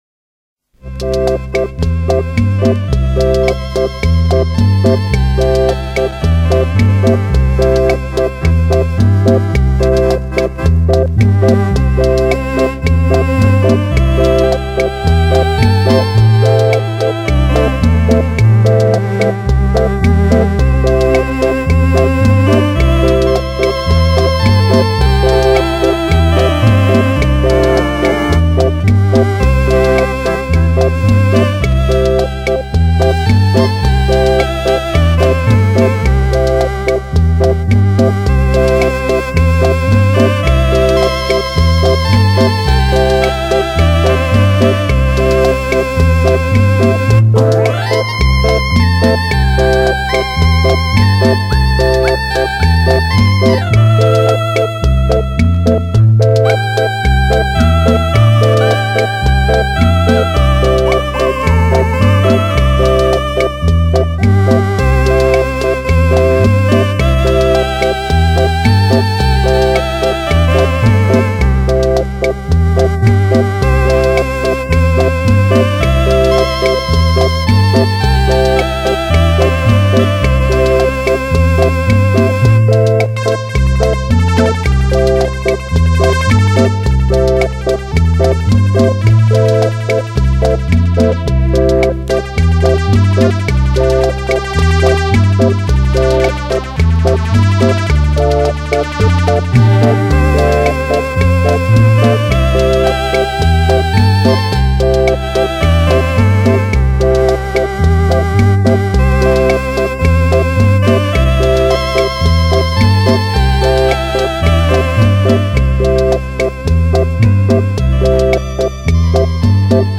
伦　巴